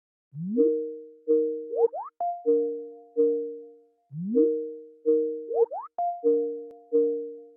Kategorien Telefon